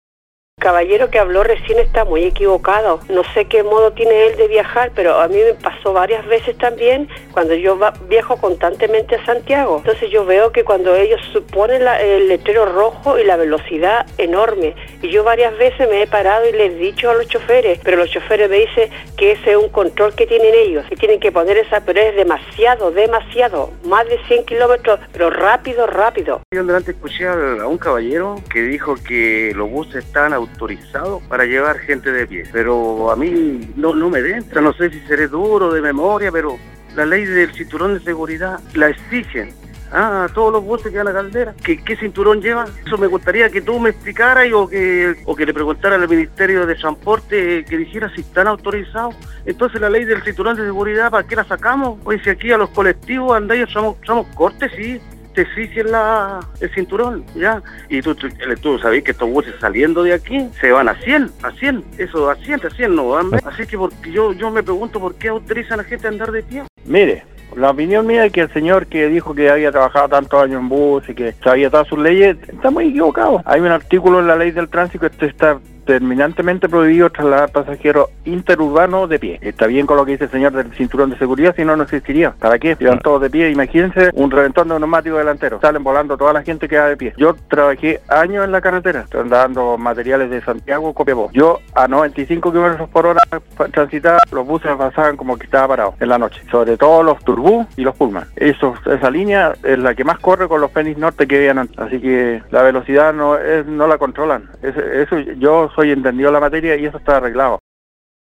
Al respecto, se recibieron muchos llamados y mensajes  donde se destacó que no existe fiscalización en los terminales de buses y muchas veces estos no cuentan con las medidas de seguridad mínimas para trasladar a los pasajeros.
Incluso, se recibió un llamado donde un ciudadano explicaba algunas normas como que estaría permitido llevar pasajeros de pie cuando son distancias corta, entre otras.